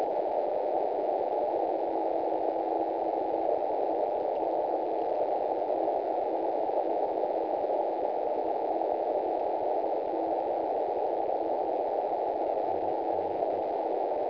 2007-09-05 18:00 GB3BAA/B  70.016          SWL    CW   TR
gb3baa tropo.wav